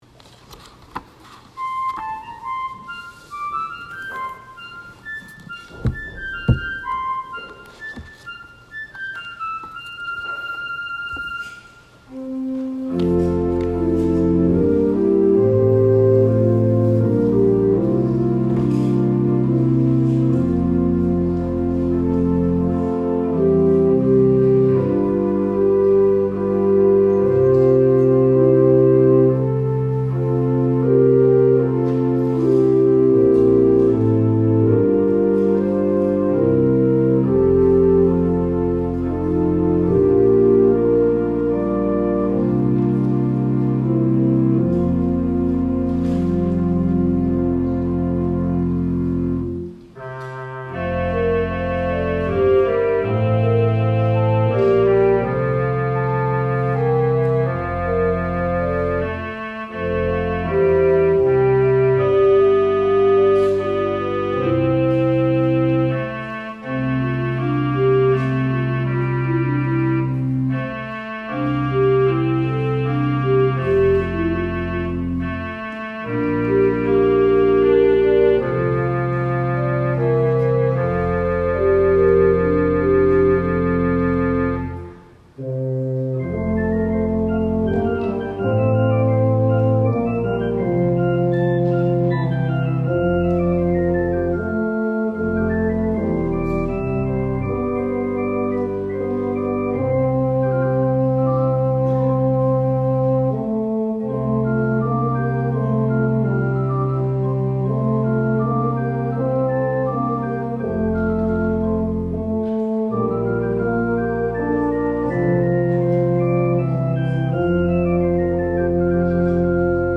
Offertory